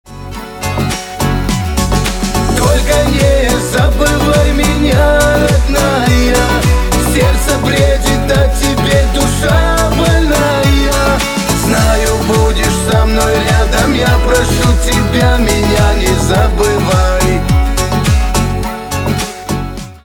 поп
гитара
кавказские